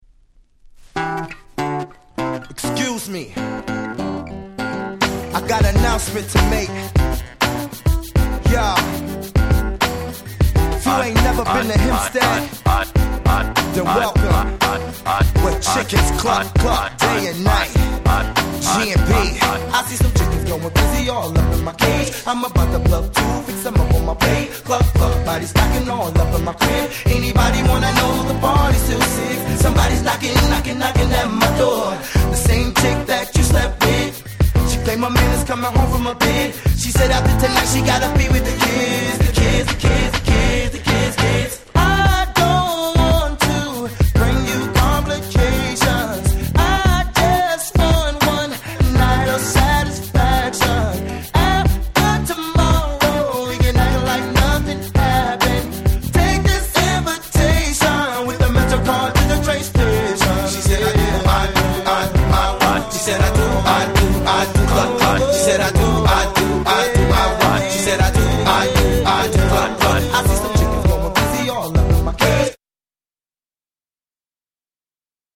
00's R&B Classic !!